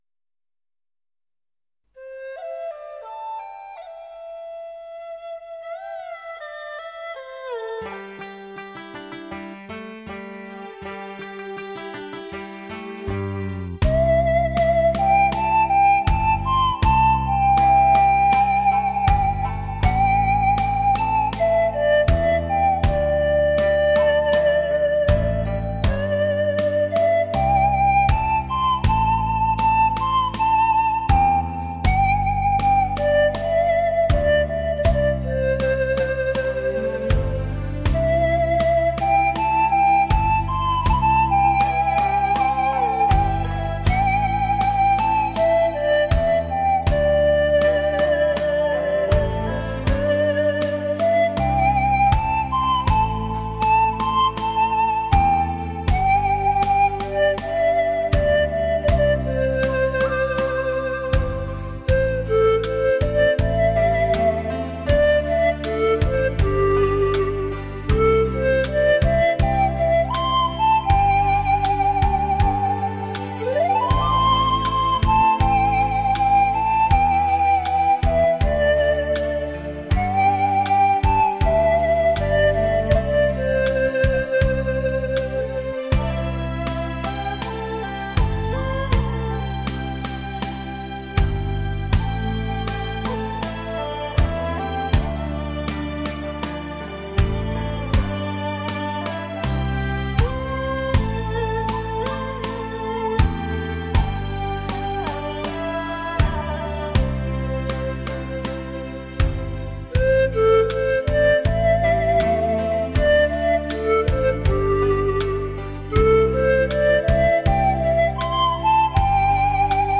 听排箫的声音，总感到是属于一个逝去的遥远的年代。